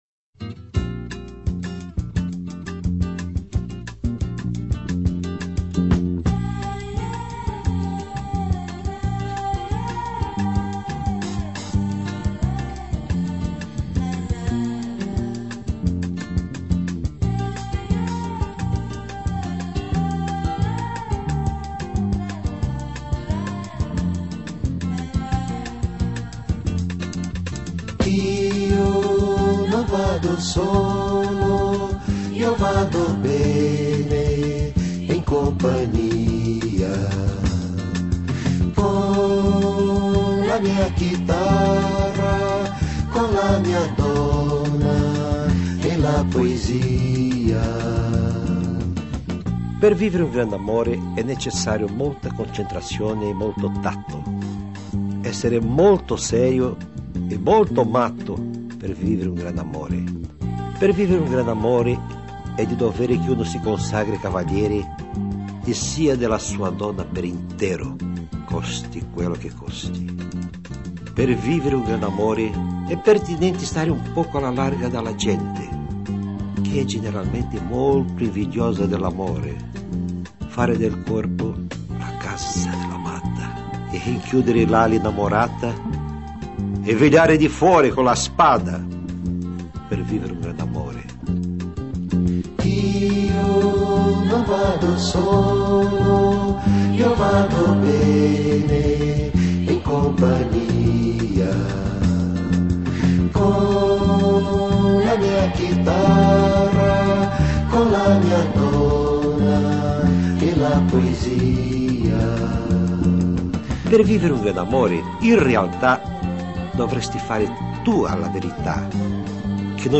poesie musicate o musica poeticata